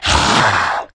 naga_warrior_attack.wav